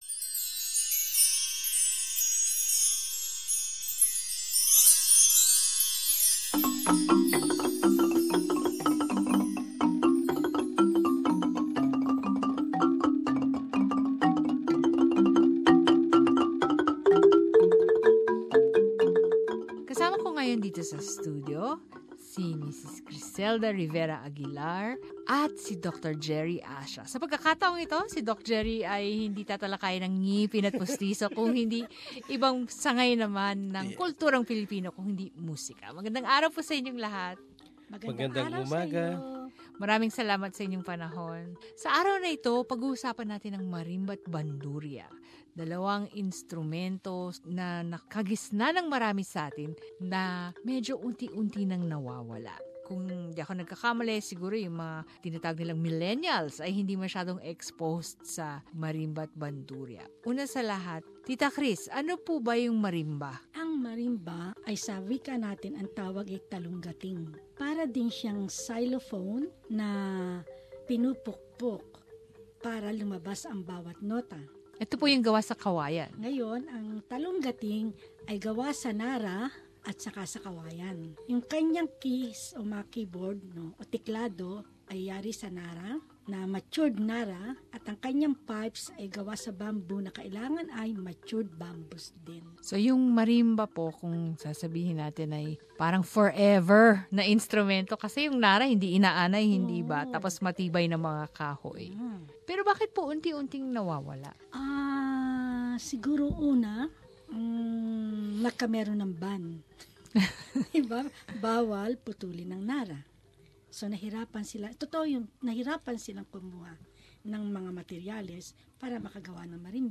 Marimba and Banduria